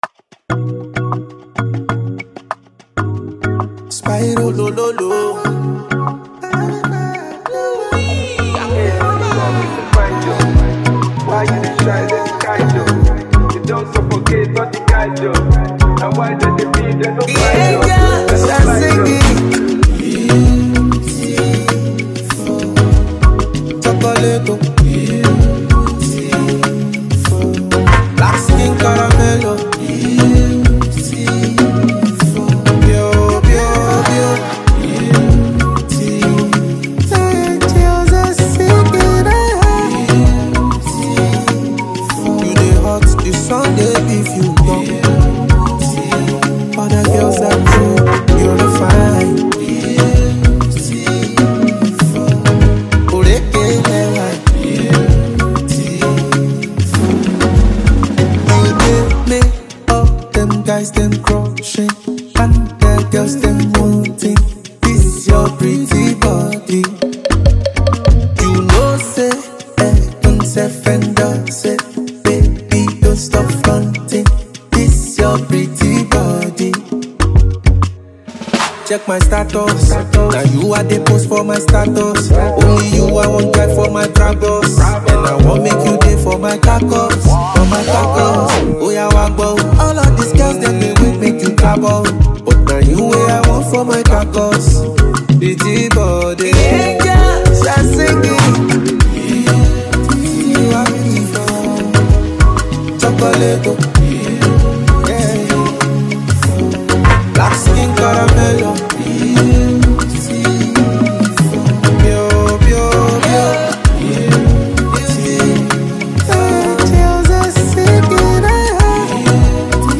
a Valentine theme song